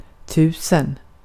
Uttal
Synonymer ett tusen Uttal Okänd accent: IPA: /ˈtʉːsɛn/ Ordet hittades på dessa språk: svenska Ingen översättning hittades i den valda målspråket.